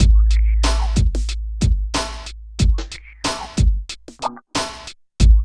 23 DRUM LP-R.wav